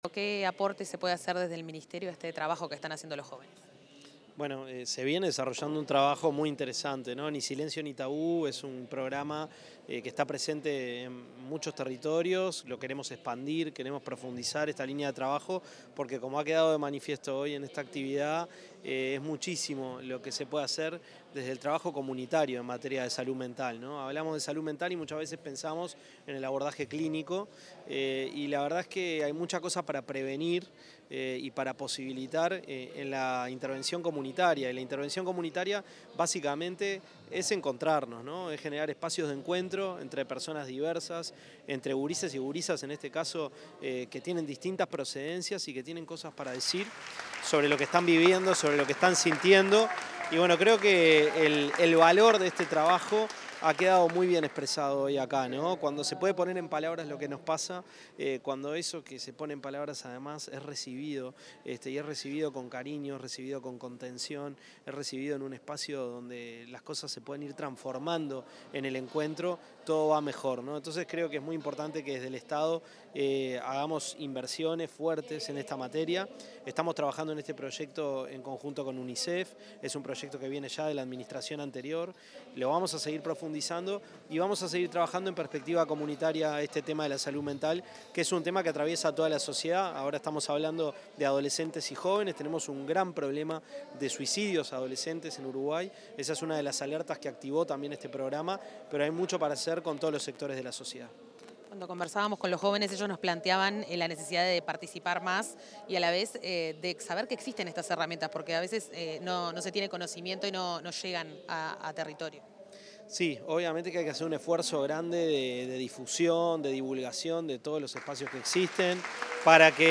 Declaraciones del ministro de Desarrollo Social, Gonzalo Civila